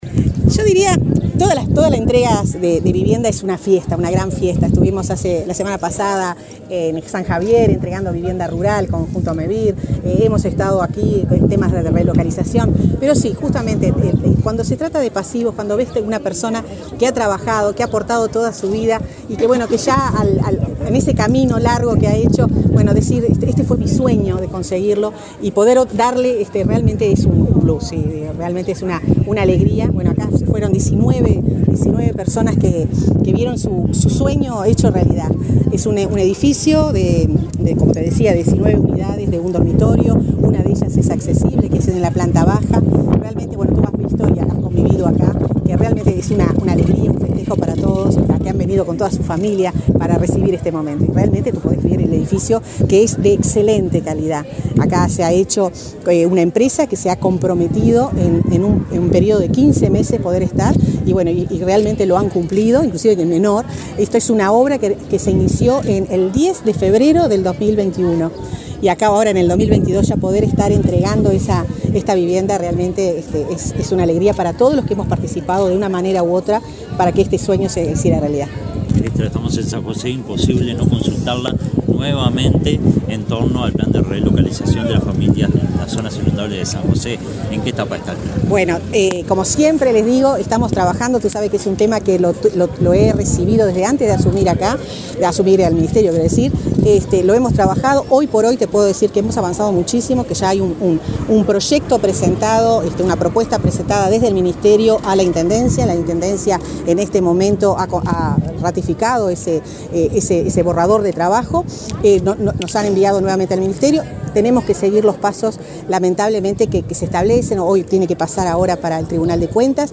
Declaraciones de la ministra de Vivienda, Irene Moreira
Luego dialogó con la prensa.